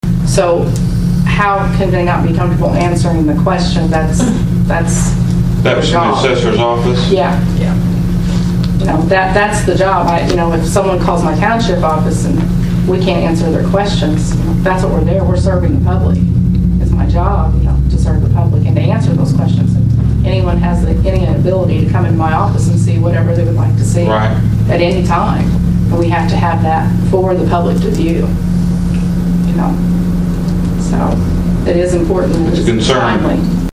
A night later at the White County Board meeting, a host of individuals spoke out in frustration.